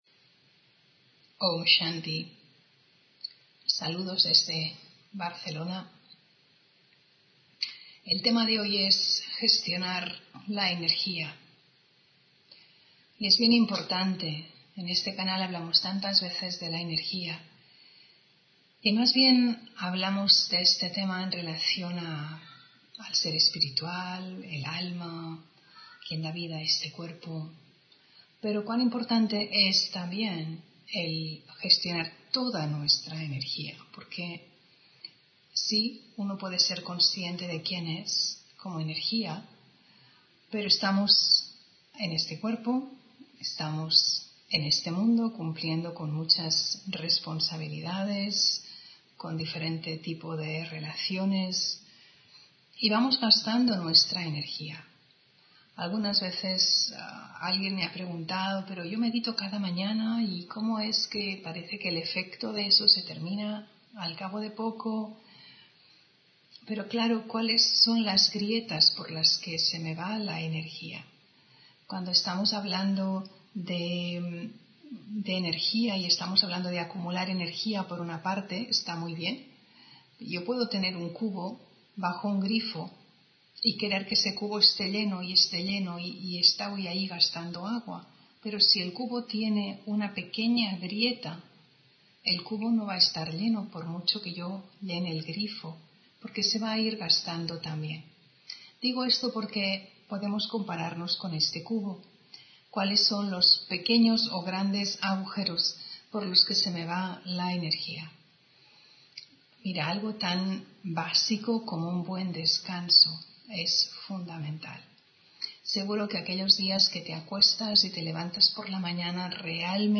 Meditación de la mañana:Sembrar semillas determinadas para el día (20 Julio 2020)